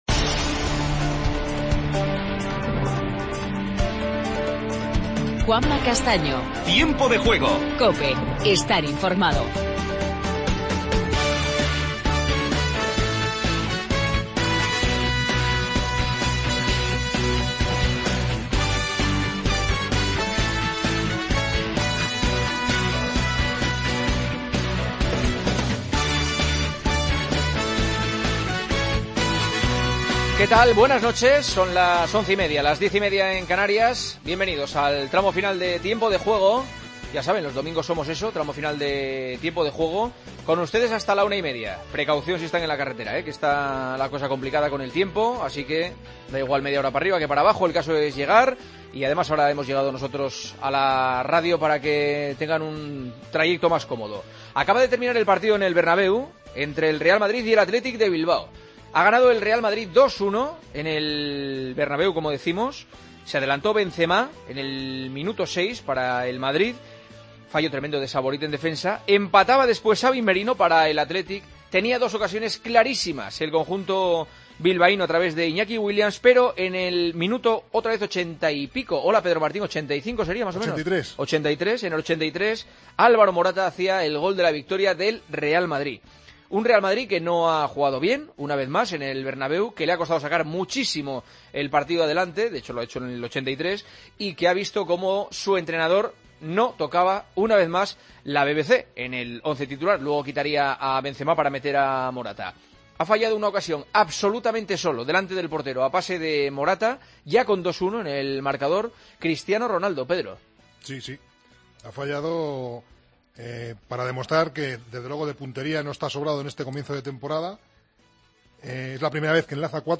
Titulares del día. Escuchamos a los protagonistas del Real Madrid-Athletic: Zidane, Morata y Mikel Rico. Hablamos con Gabi, tras la primera derrota en Liga del Atlético.